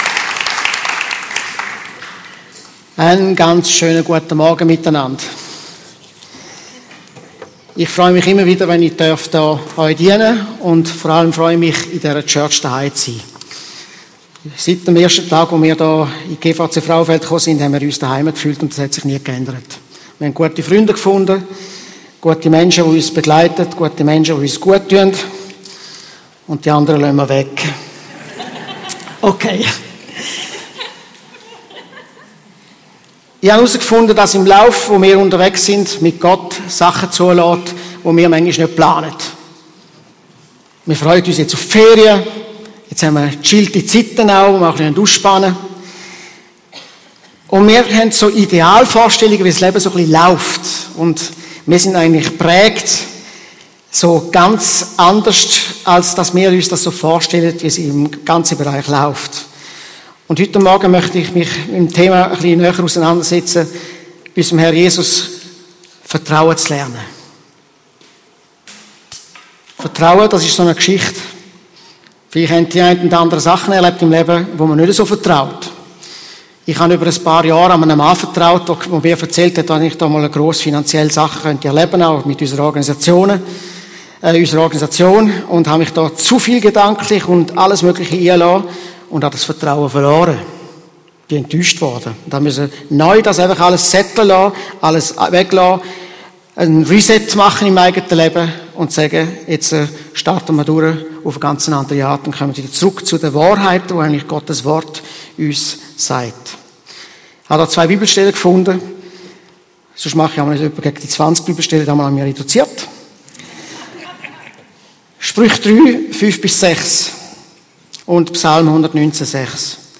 Regelmässig die neusten Predigten der GvC Frauenfeld